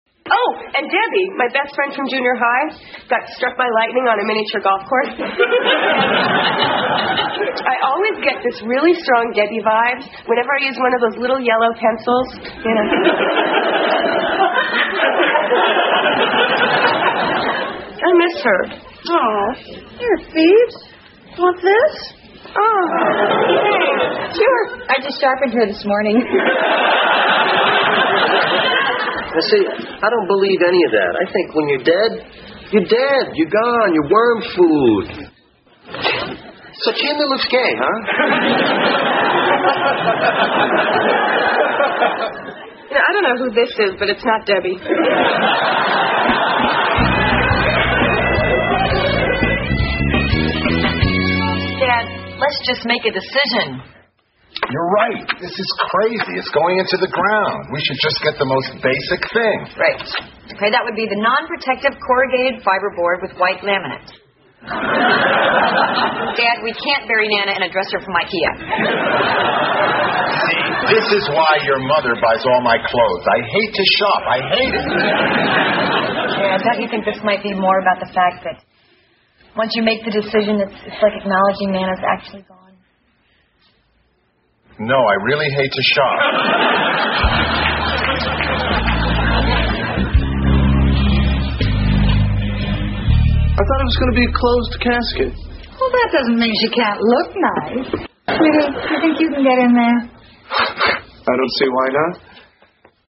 在线英语听力室老友记精校版第1季 第91期:祖母死了两回(5)的听力文件下载, 《老友记精校版》是美国乃至全世界最受欢迎的情景喜剧，一共拍摄了10季，以其幽默的对白和与现实生活的贴近吸引了无数的观众，精校版栏目搭配高音质音频与同步双语字幕，是练习提升英语听力水平，积累英语知识的好帮手。